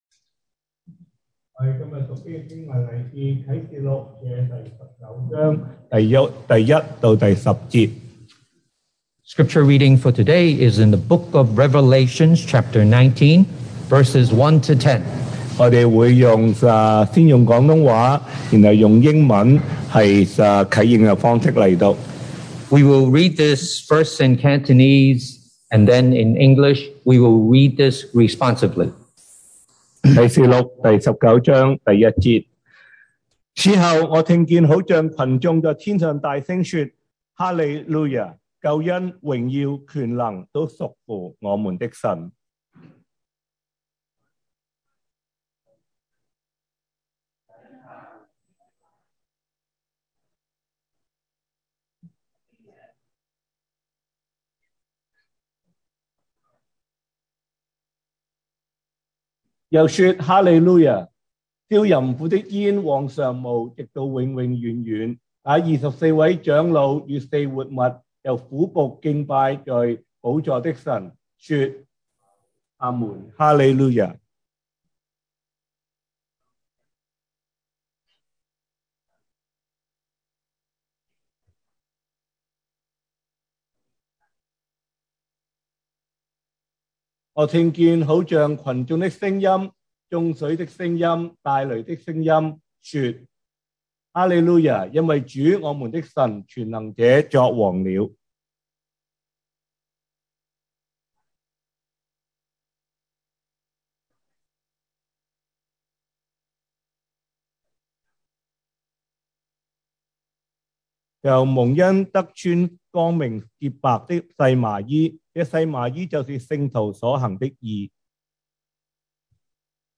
2022 sermon audios
Passage: Revelation 19:1-10 Service Type: Sunday Morning